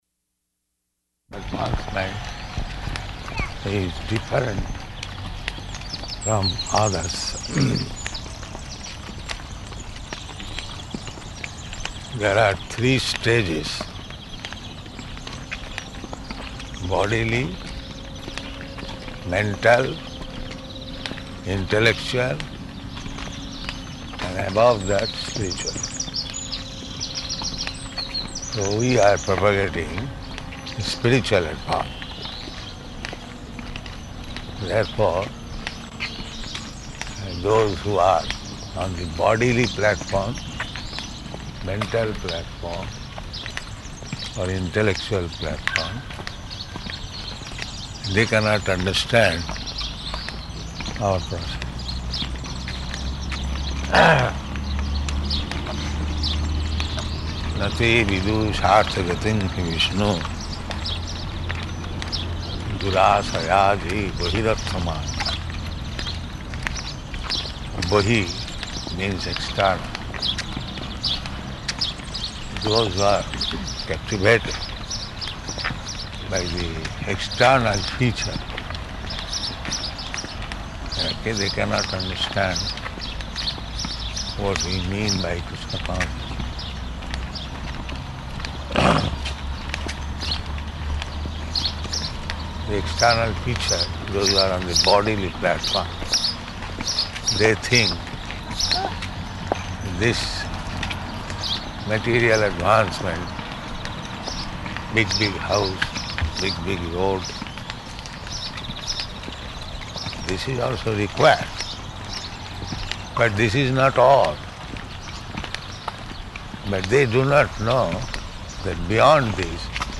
Morning Walk --:-- --:-- Type: Walk Dated: May 29th 1974 Location: Rome Audio file: 740529MW.ROM.mp3 Prabhupāda: ...advancement is different from others.